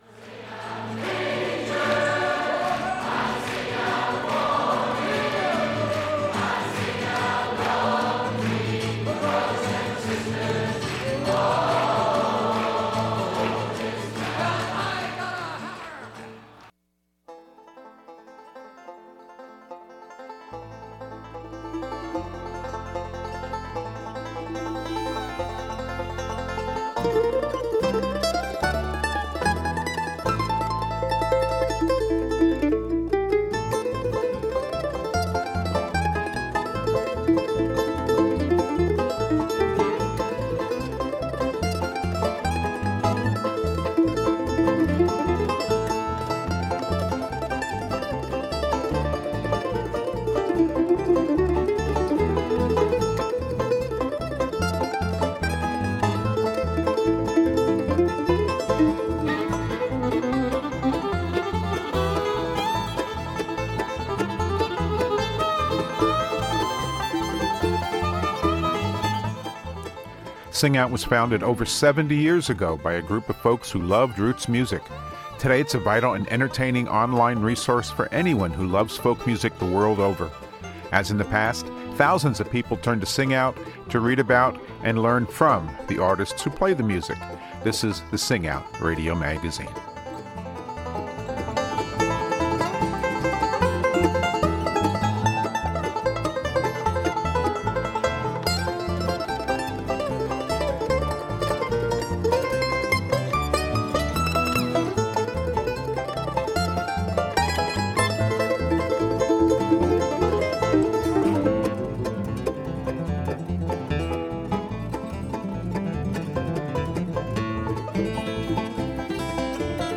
Songs about baseball capture some of the spring joy in this edition of the podcast.